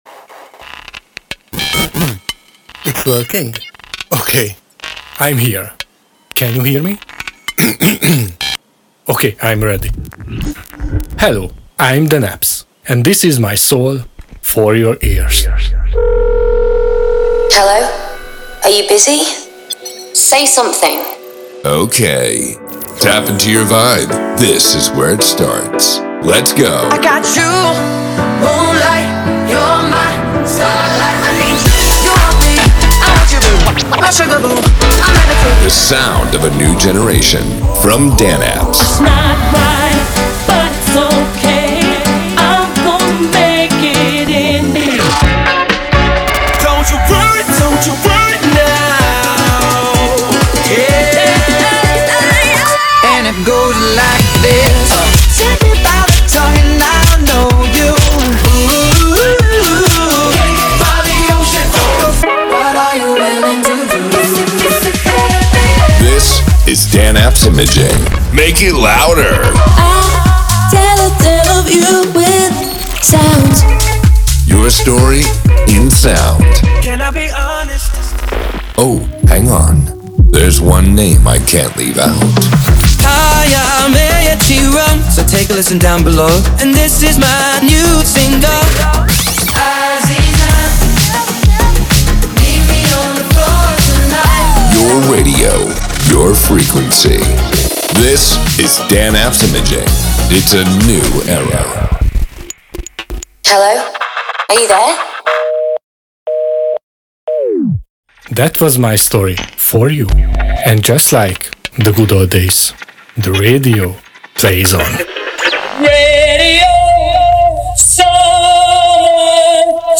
DANAPS IMAGING WELCOME PROMO - 2025.05